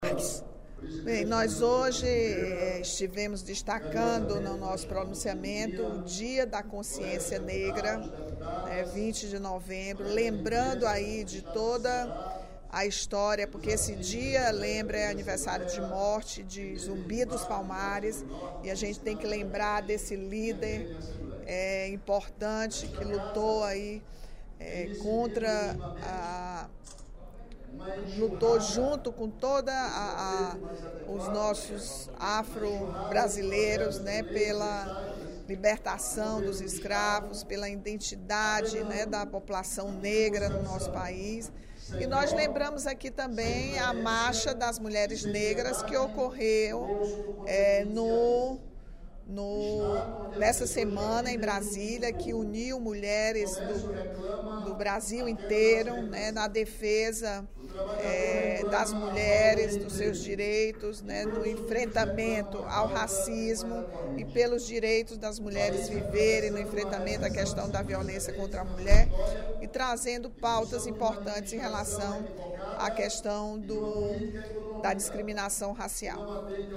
A deputada Rachel Marques (PT) destacou nesta sexta-feira (20/11), durante o primeiro expediente da sessão plenária, o Dia Nacional da Consciência Negra, comemorado hoje, 20 de novembro.